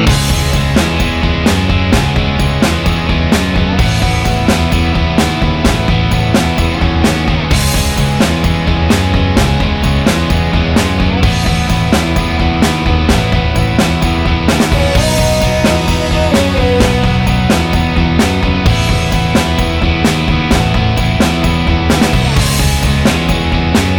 No Backing Vocals Rock 4:19 Buy £1.50